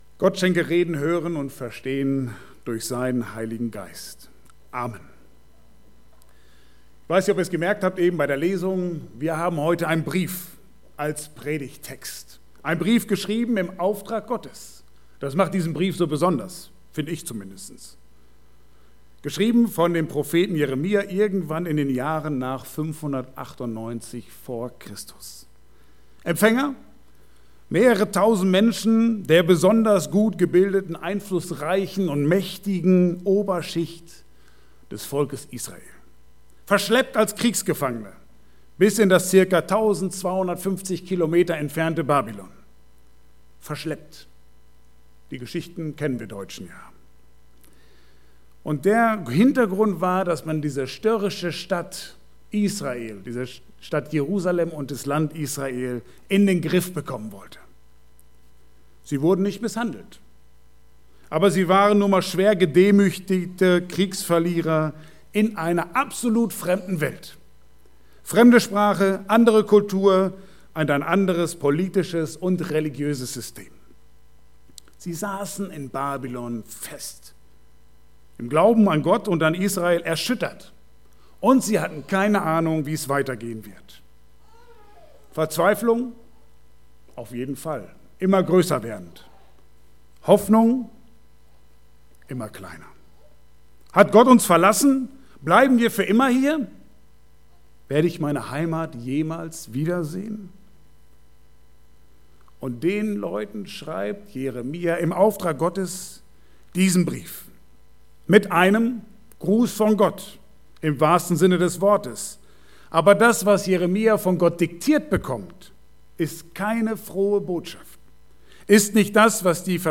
Passage: Jeremia 29,1+4-7-14 Dienstart: Gottesdienst « Licht an